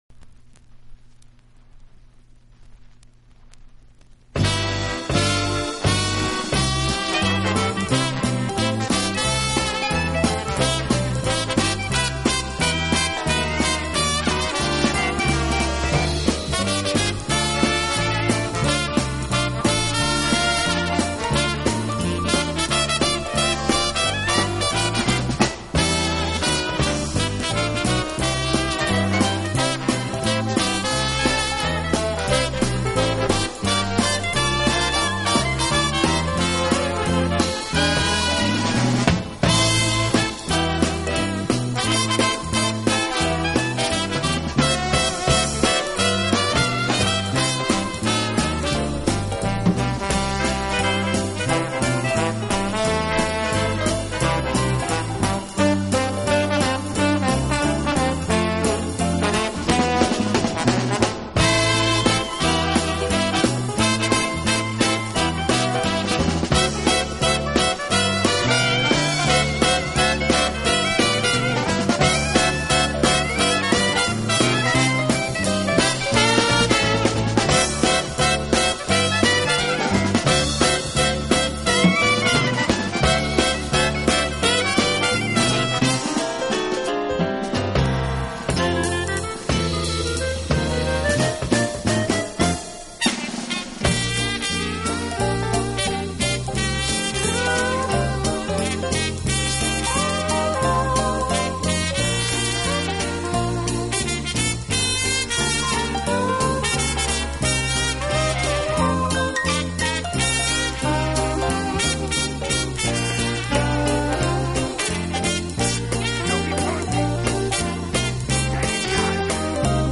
轻快、柔和、优美，带有浓郁的爵士风味。